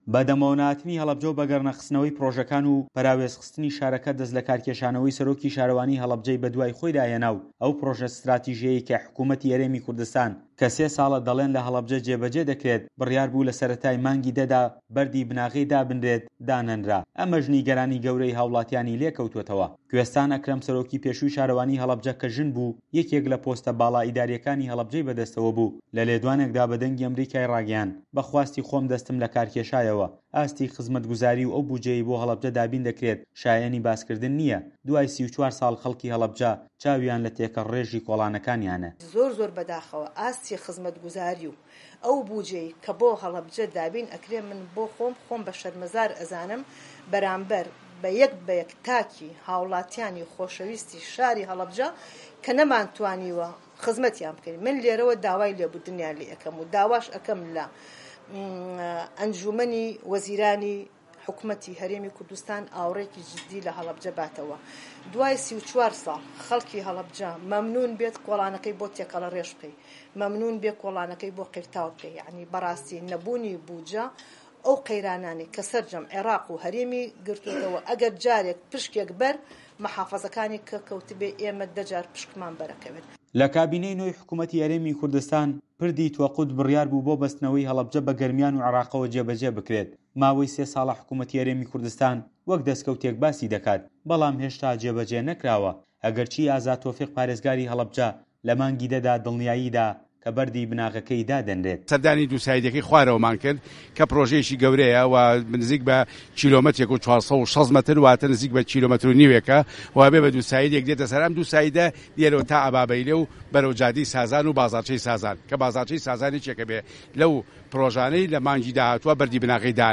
ڕاپۆرتی پەیامنێر